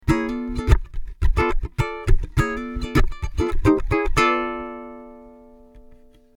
To add the groove to a chord you can use a one fret slide-in.
Scrubbing MP3
slide-funky.mp3